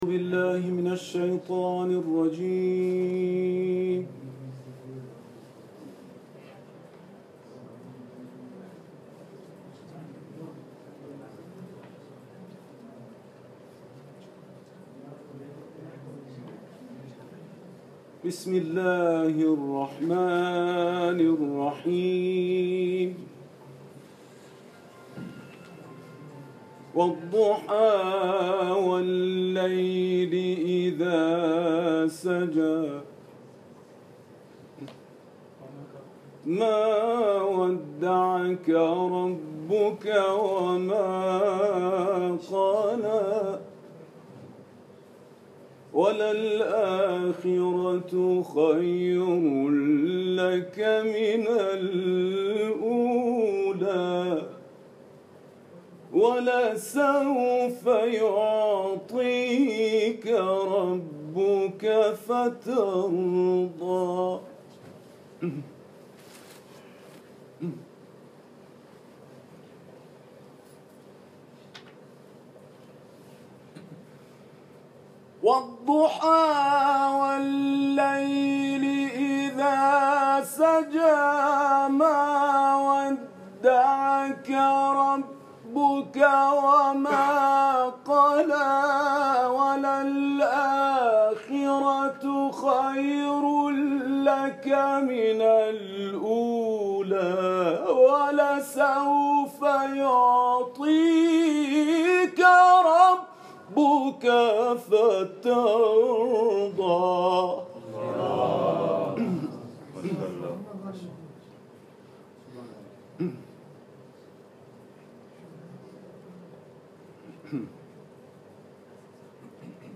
تلاوت
در اختتامیه اولین جشنواره بین‌المللی قرآن دانشجویان هند